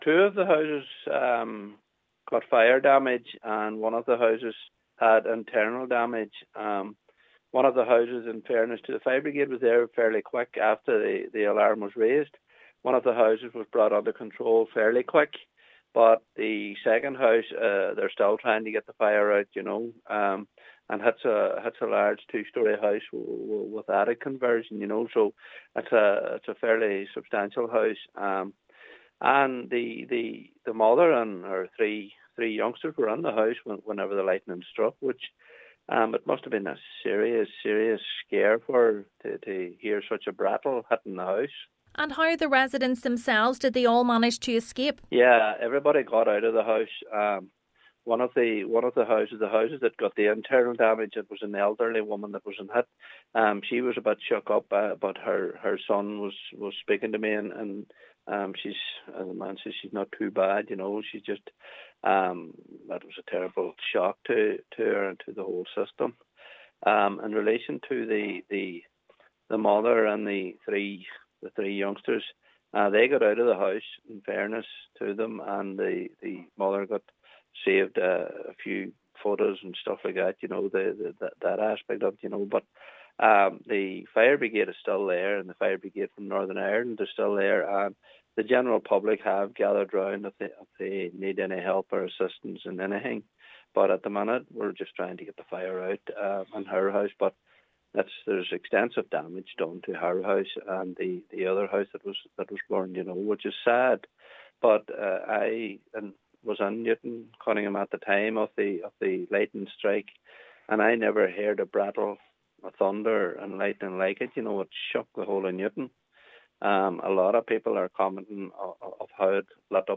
Councillor Paul Canning says it was a very frightening experience for those within the house at the time: